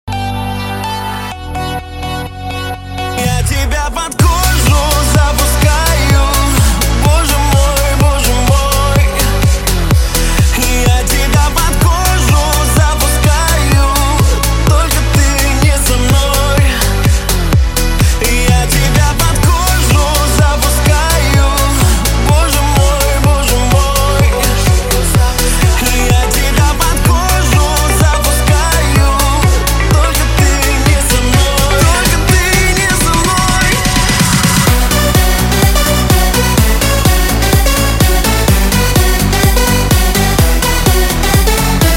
• Качество: 128, Stereo
поп
dance
Танцевальный трек